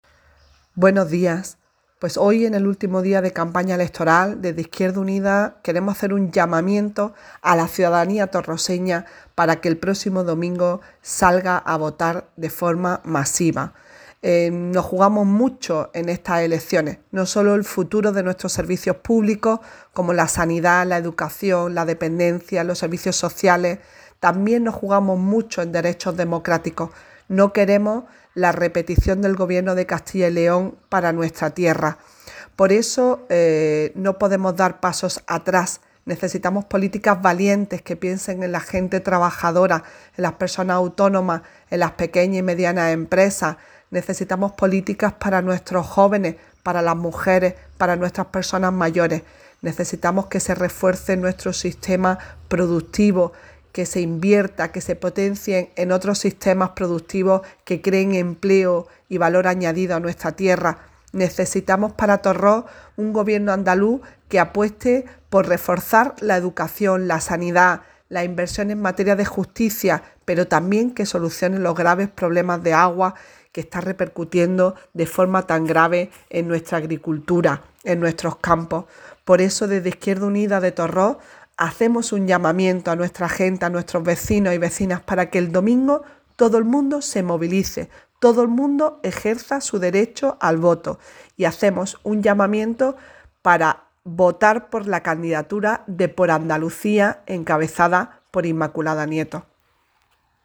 La portavoz del grupo municipal de Izquierda Unida, María José Prados, hace un llamamiento para que todo el mundo salga a votar el próximo domingo.